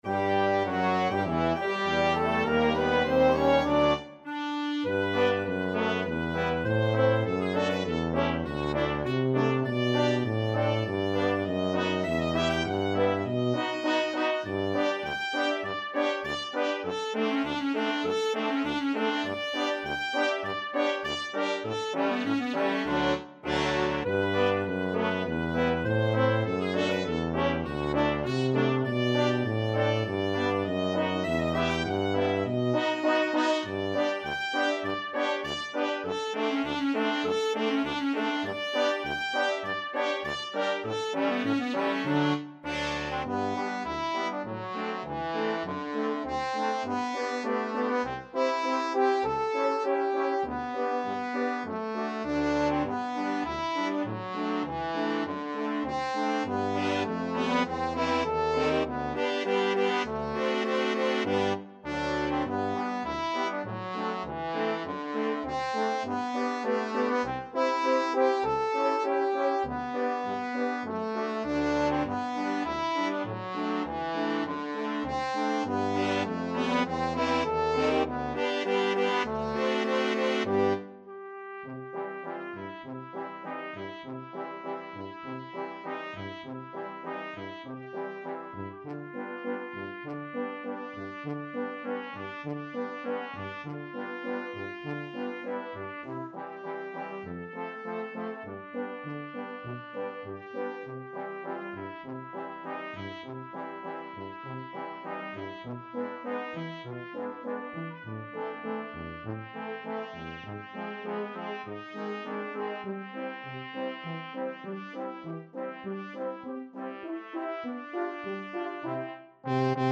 ClarinetTrumpet
EuphoniumFrench Horn
Trombone
Tuba
Quick March = c.100
2/2 (View more 2/2 Music)